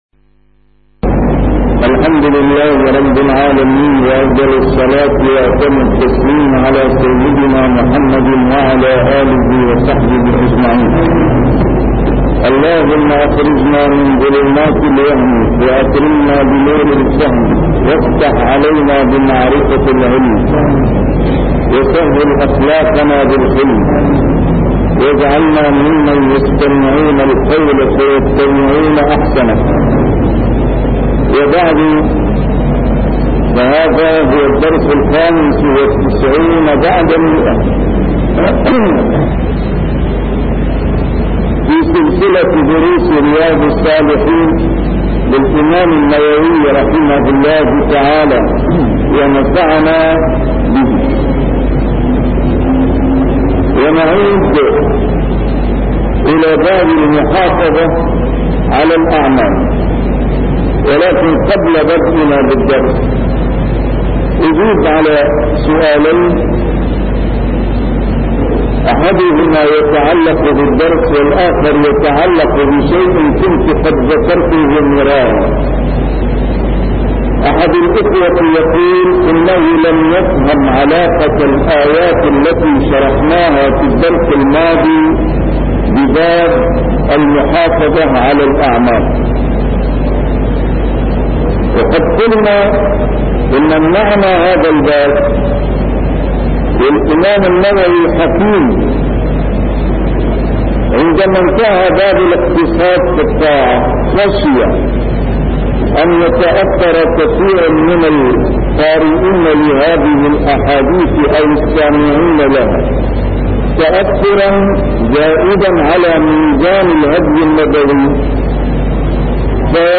A MARTYR SCHOLAR: IMAM MUHAMMAD SAEED RAMADAN AL-BOUTI - الدروس العلمية - شرح كتاب رياض الصالحين - 195- شرح رياض الصالحين: المحافظة على الأعمال الصالحة